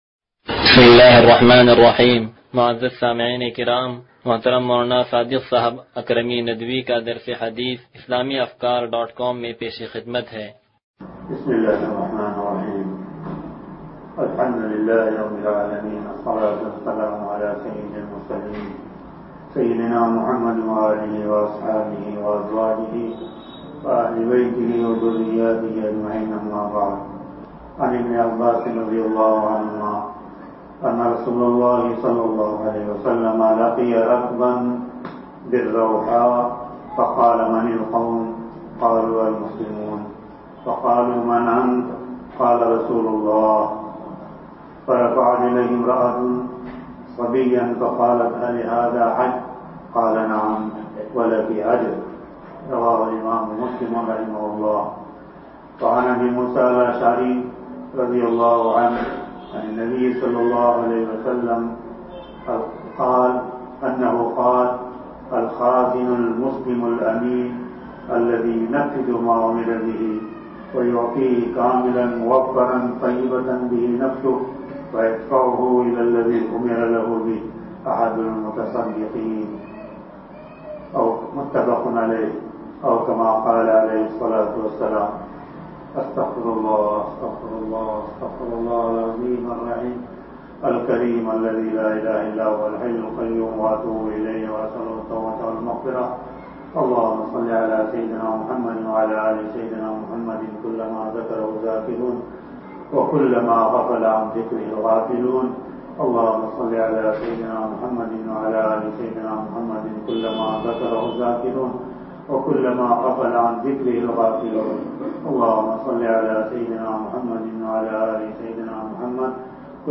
درس حدیث نمبر 0194
(سلطانی مسجد)